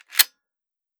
Foley / 30-30 Lever Action Rifle - Loading 004.wav